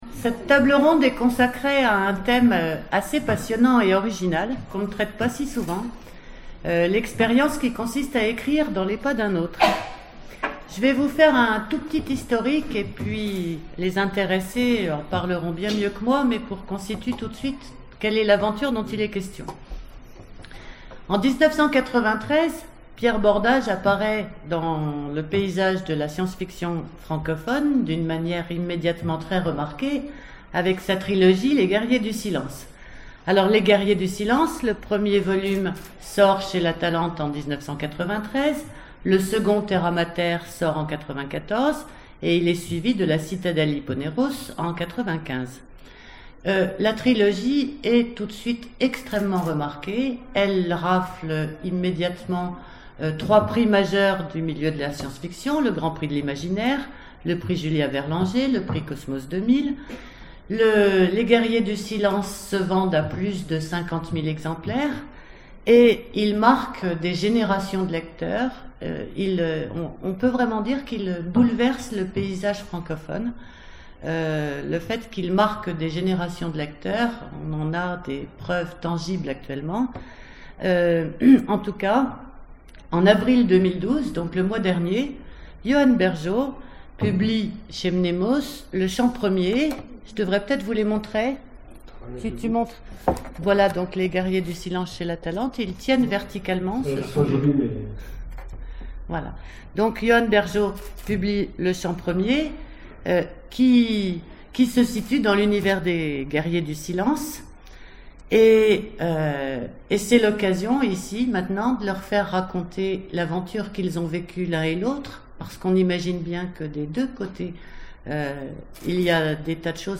Imaginales 2012 : Conférence Les Univers partagés, écrire dans les pas d'un autre...